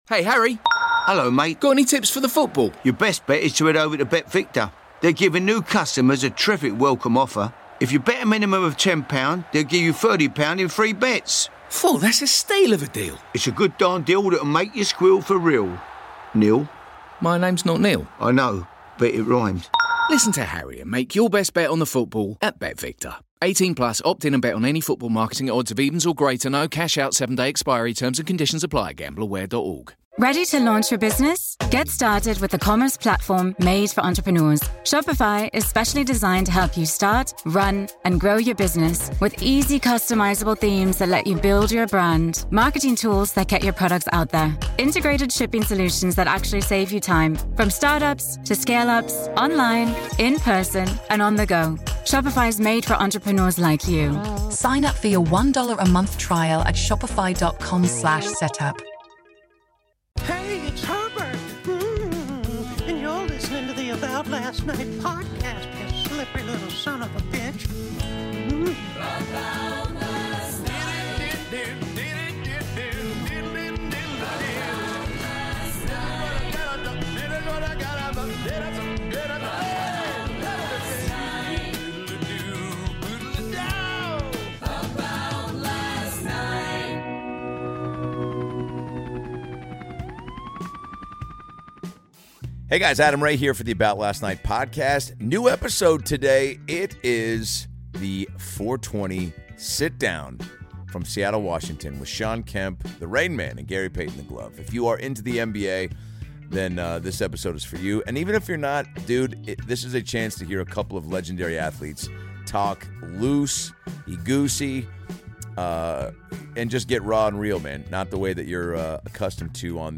Adam Ray brings in April 20th, 2025, with Shawn Kemp and Gary Payton. Filmed live at Tacoma Comedy Club.